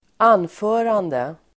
Uttal: [²'an:fö:rande]